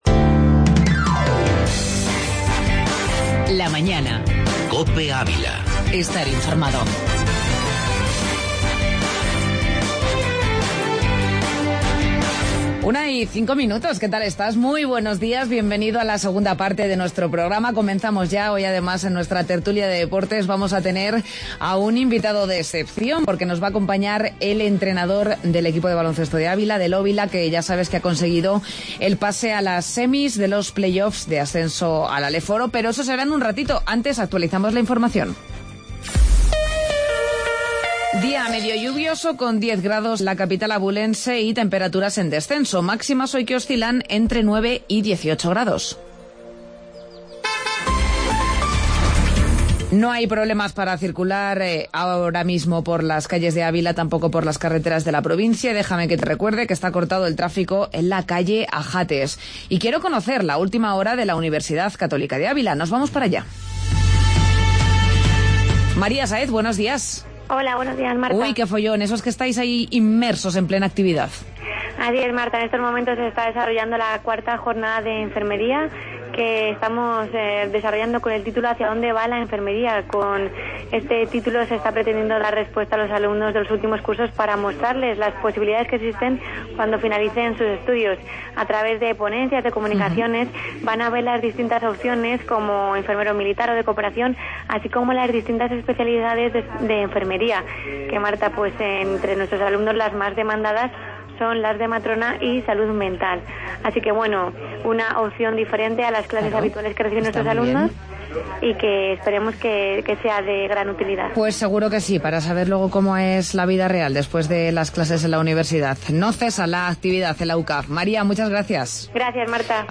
AUDIO: Garbantel y tertulia deportiva